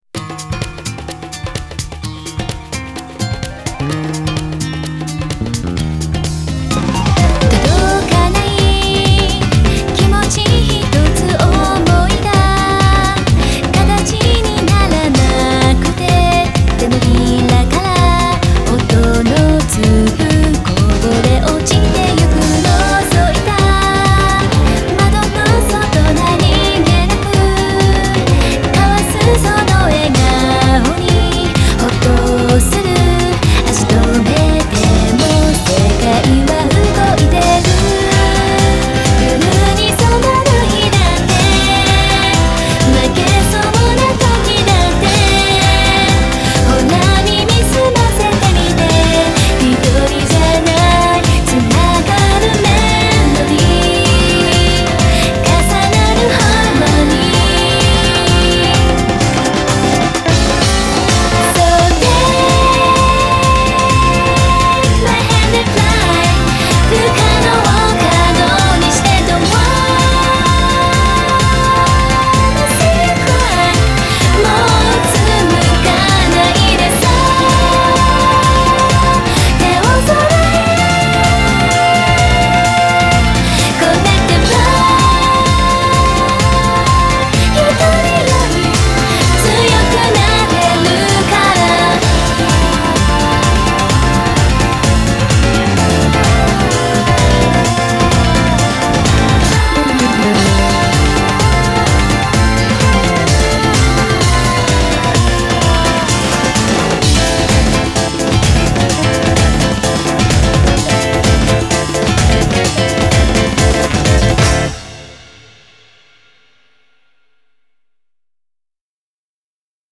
BPM128
Audio QualityPerfect (High Quality)
Genre: LOUNGE POP.
this song mixes a latin groove with sweet vocals